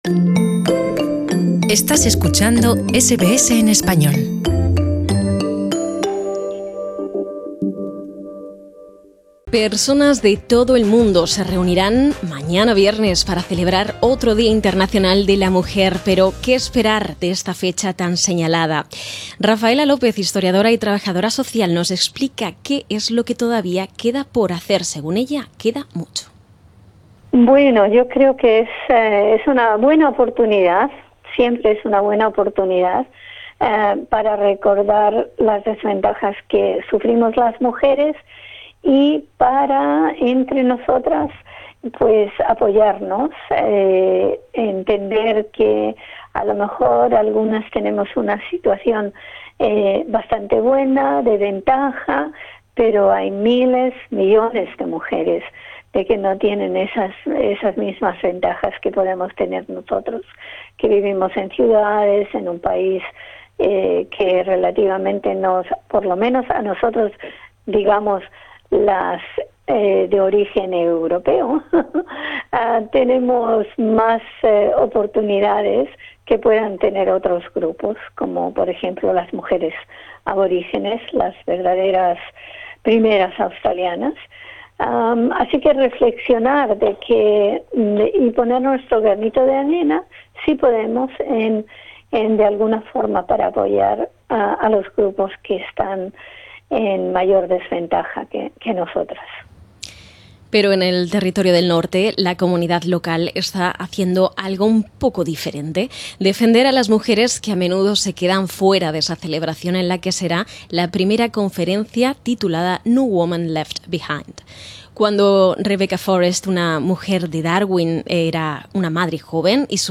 Escucha más arriba a la investigadora y a diferentes participantes de la conferencia que tendrá lugar mañana en Territorio del Norte.